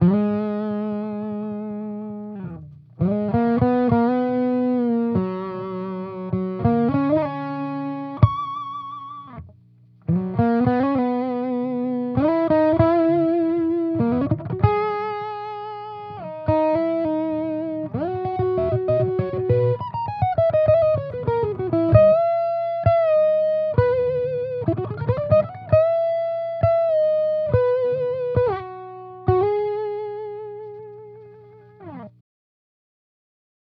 Here are a few quick demos of my custom overdrive/distortion pedals for your listening pleasure.
Gold pedal 1N60 diodes with boost and EQ
As you can probably hear, the gold pedal has a lot less gain, even with a boost, so it’s better equipped for blues stuff than rock/metal solos.
I used a Sennheiser MD441-U microphone on-axis, edge of speaker cap, and about an inch away from the grill.
gold-pedal-1n60-diodes-with-boost-and-eq.wav